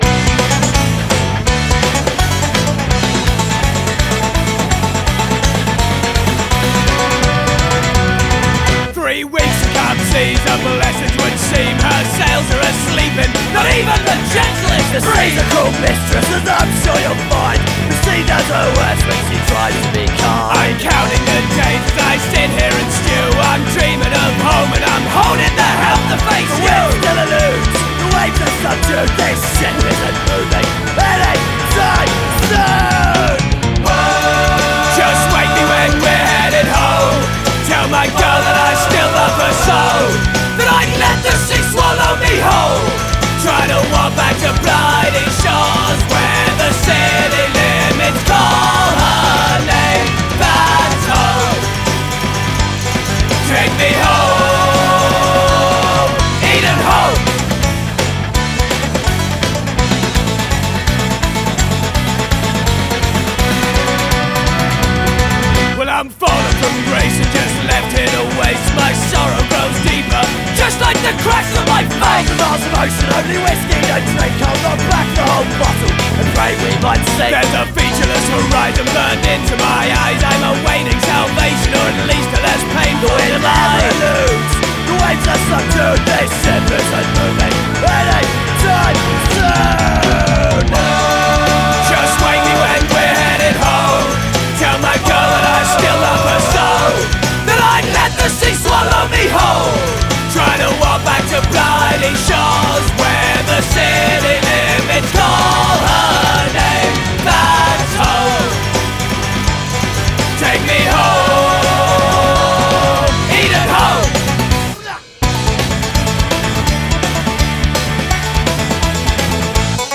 mandolins and acoustics
a very loud collection of folky songs.
The punkiness comes around for several rounds